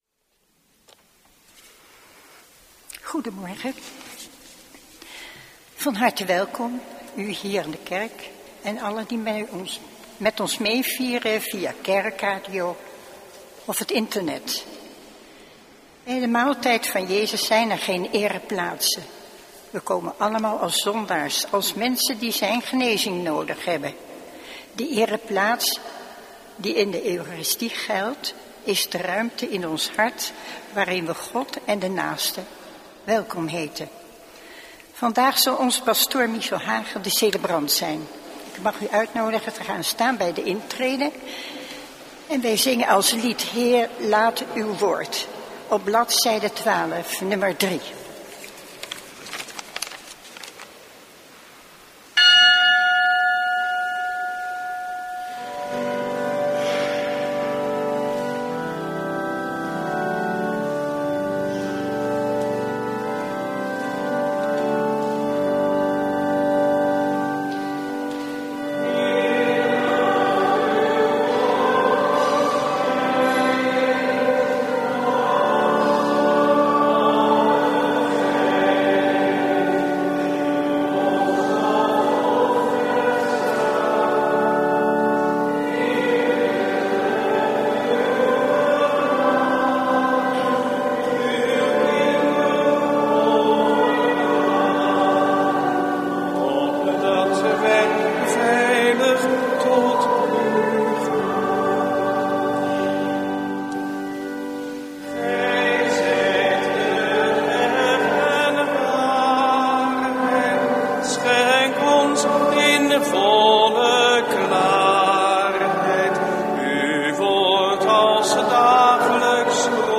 Eucharistieviering beluisteren vanuit de H. Willibrordus te Wassenaar (MP3)